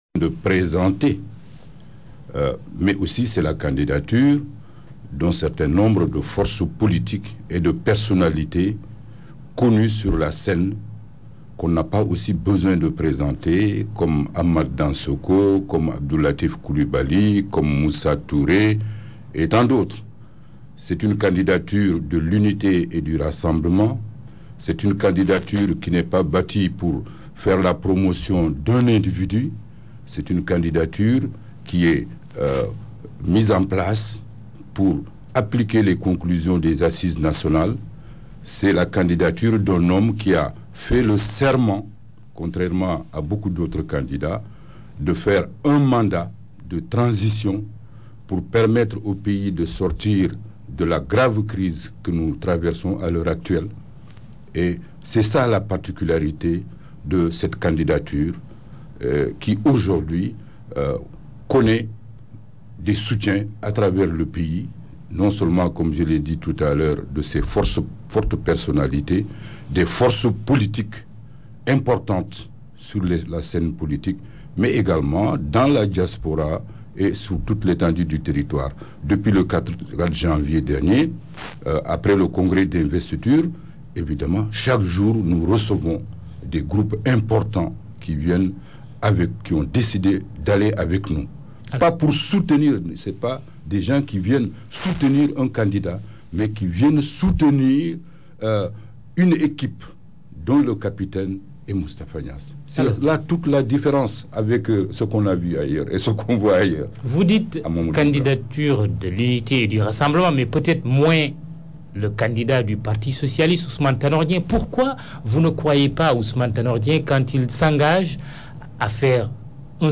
Abdoulaye Bathily, par ailleurs, secrétaire général de la Ligue Démocratique (LD) l’a fait savoir lors de l’émission dominicale de la RFM (radio privée) «Grand jury».